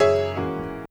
Keys_01.wav